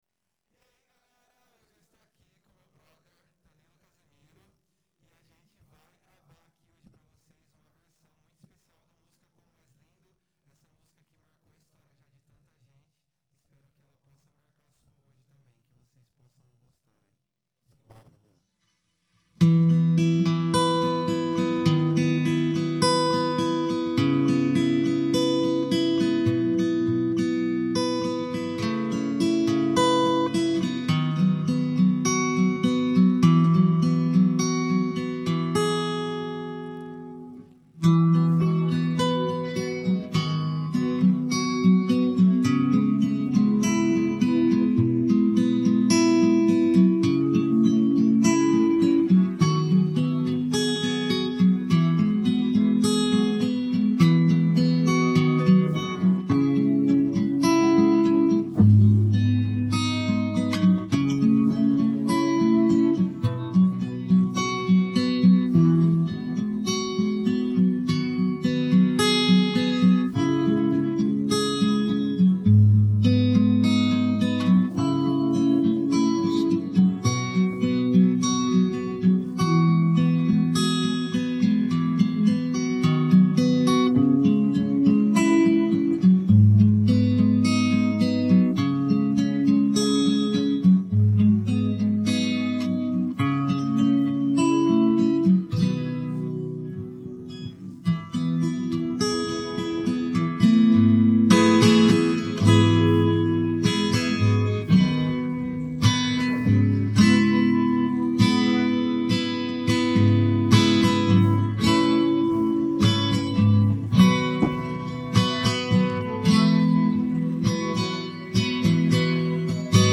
Hintergrundmusik